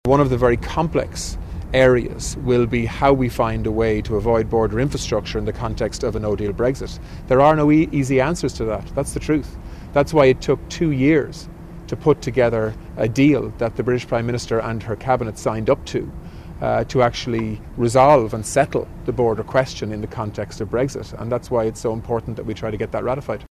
Tánaiste Simon Coveney told the Dail the border question is one that still has to be resolved………..